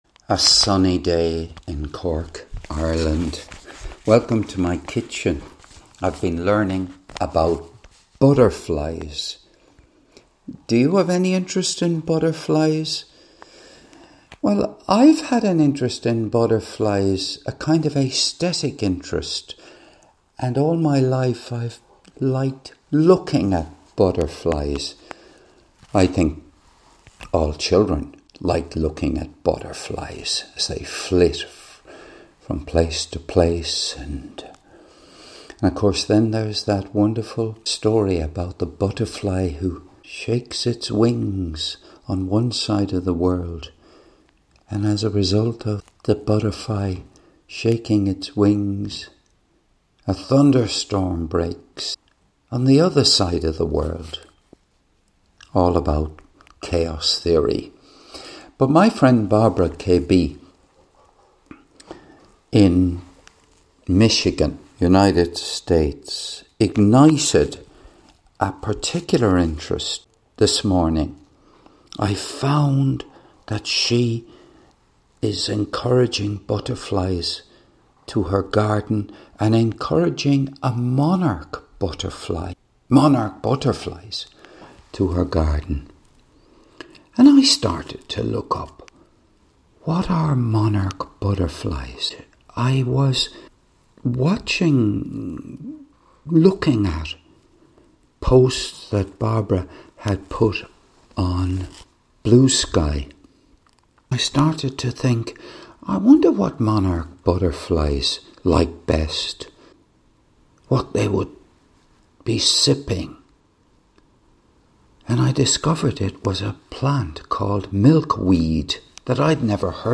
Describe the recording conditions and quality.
This was recorded in my kitchen on Tuesday morning